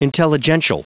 Prononciation audio / Fichier audio de INTELLIGENTIAL en anglais
Prononciation du mot : intelligential